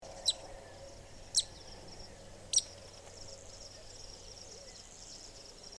It is thought to be the world’s 3rd most common kingfisher and a noisy bird.